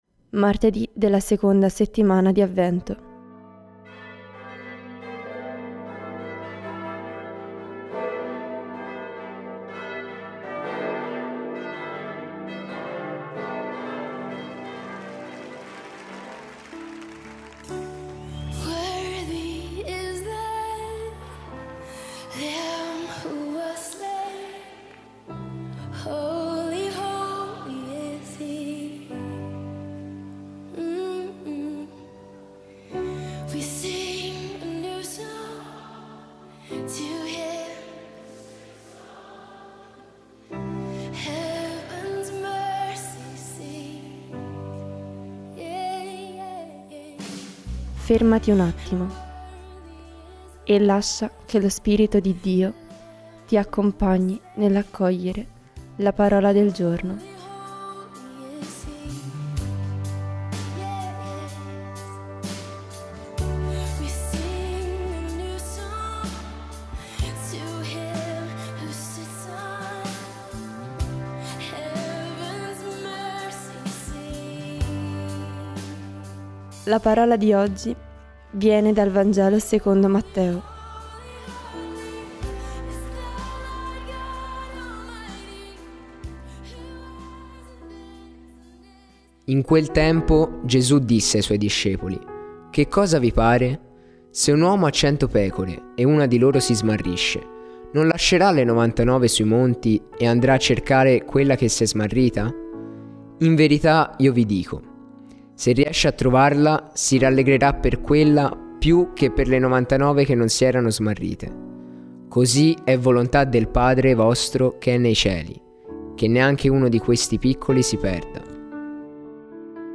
Voci narranti
Musica di Chris Tomlin ft. Kari Jobe: Revelation Song